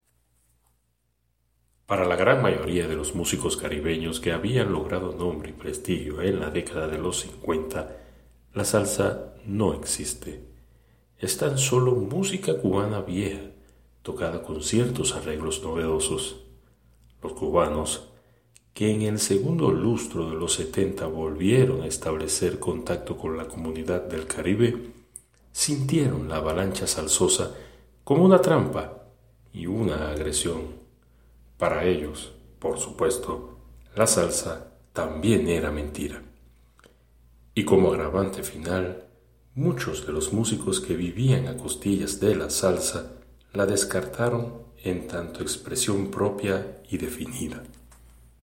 Voz fuerte y clara.
Sprechprobe: eLearning (Muttersprache):